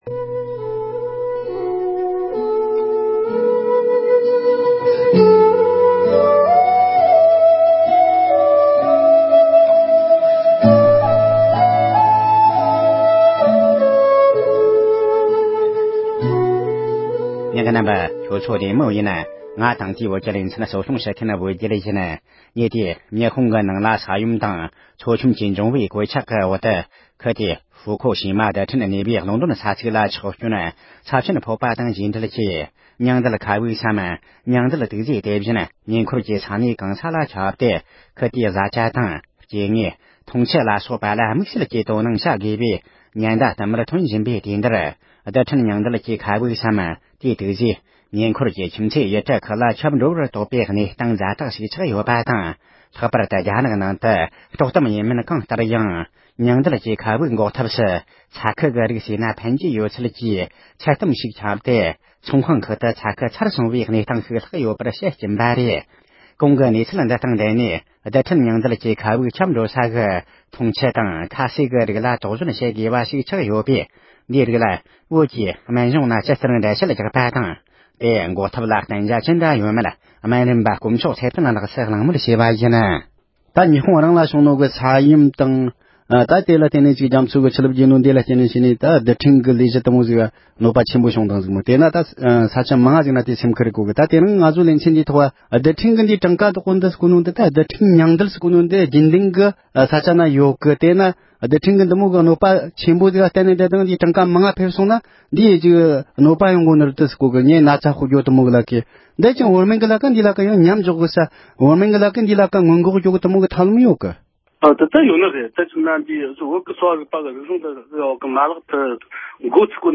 ཉི་ཧོང་གི་ཕུའུ་ཁུ་ཞི་མའི་རྡུལ་འཕྲན་ཐད་གླེང་མོལ།